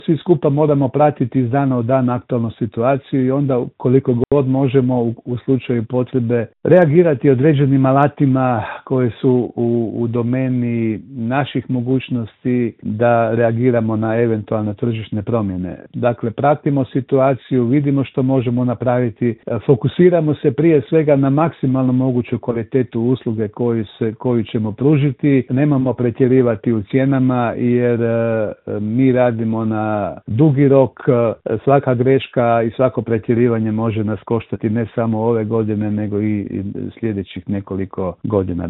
u telefonskom Intervjuu Media servisa naglašava da možemo biti optimistični, ali oprezni.